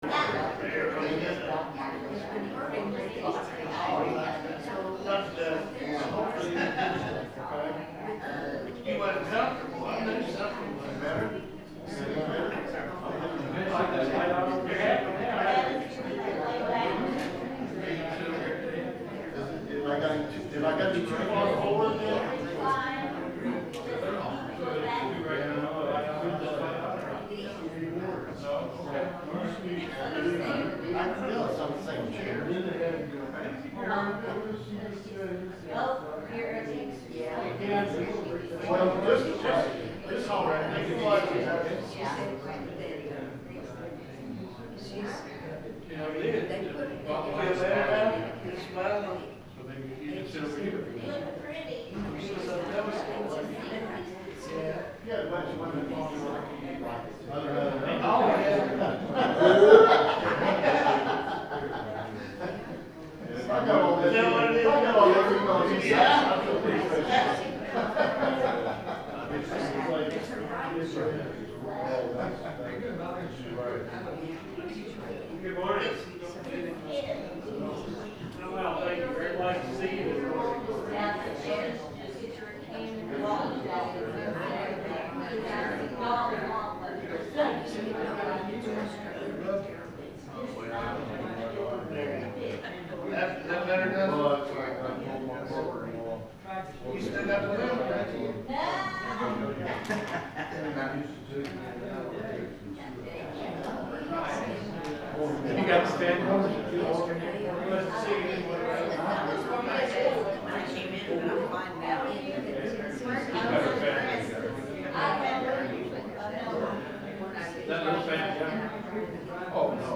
The sermon is from our live stream on 10/12/2025